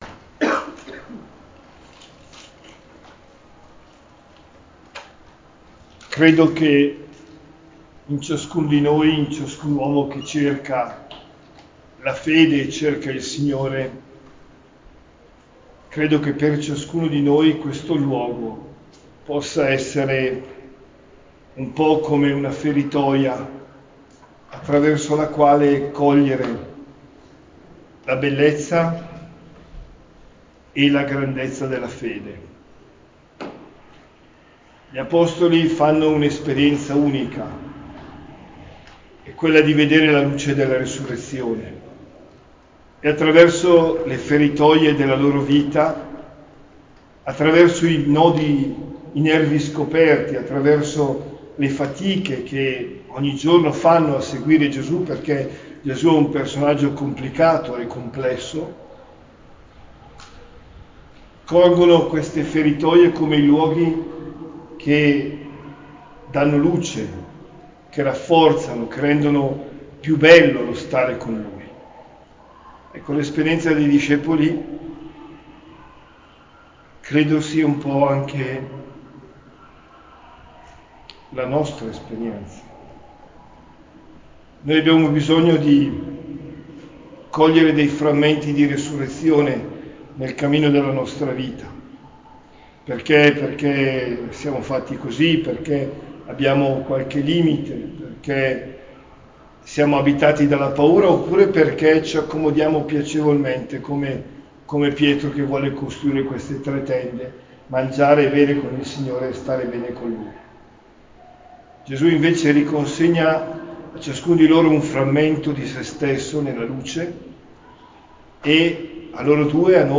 OMELIA DELLA CELEBRAZIONE AL TABOR